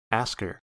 Ääntäminen
US : IPA : /ˈæsk.ɚ/ UK : IPA : /ˈɑːsk.ə/ RP : IPA : /ˈɑːsk.ə/